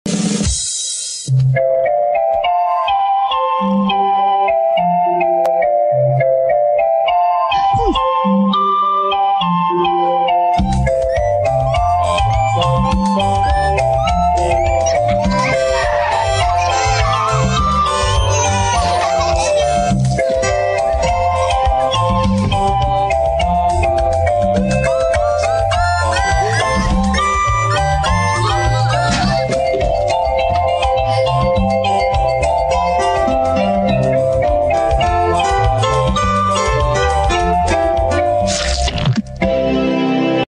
شارة البداية